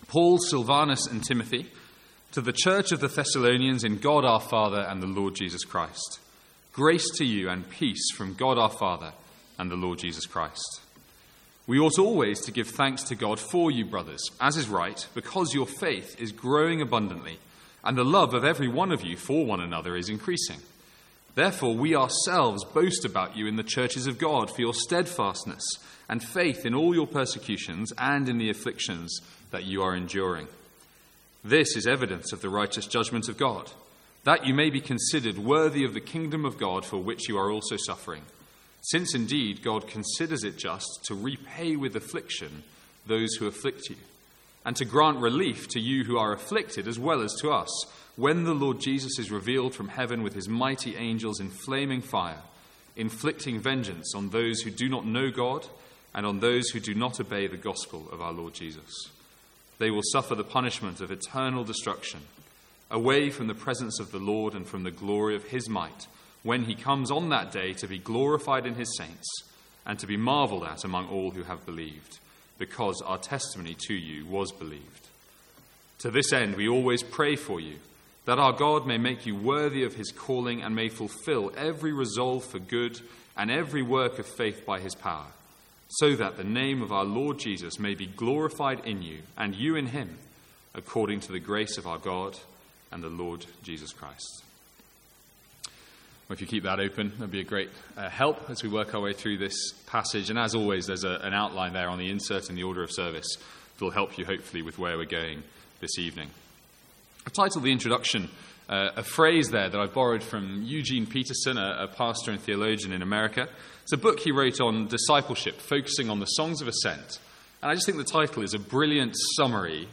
Sermons | St Andrews Free Church
From the Sunday evening series in 2 Thessalonians.